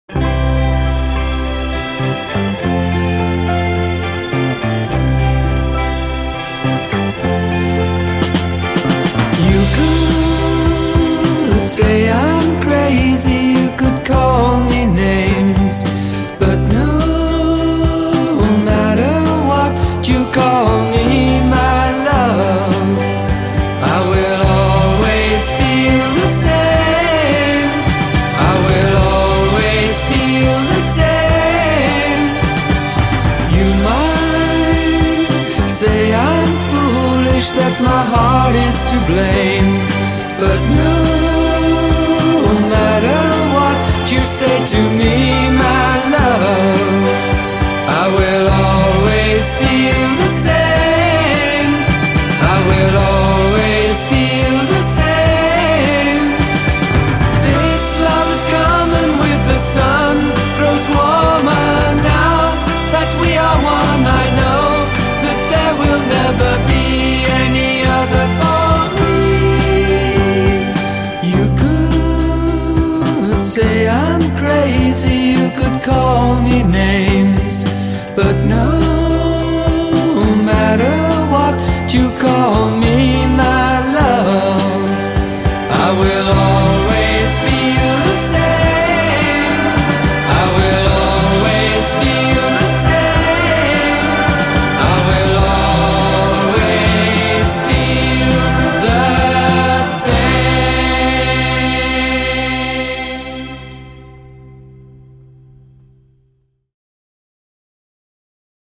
pastoral British folk rock, otherwise known as Acid Folk.